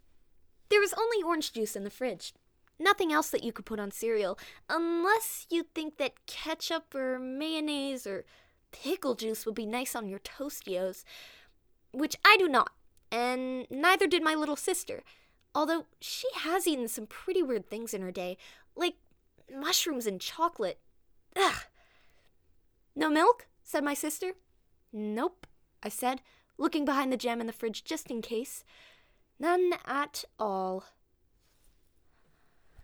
Narration - ANG